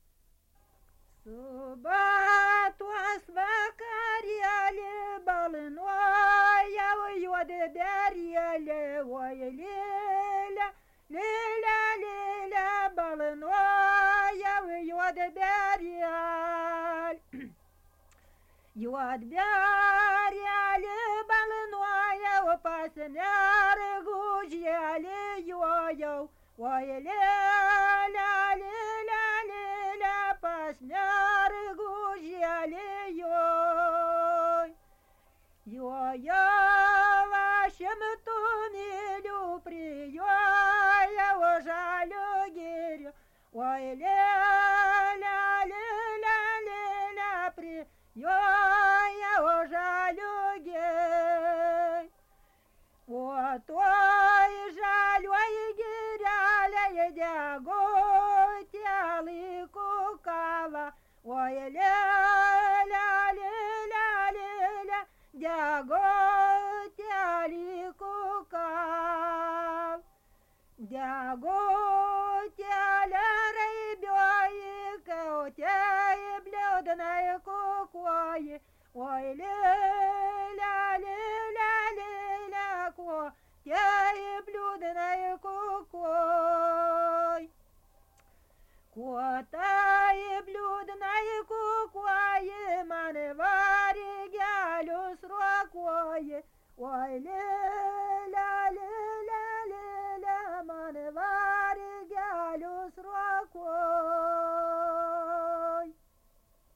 Subject daina
Atlikimo pubūdis vokalinis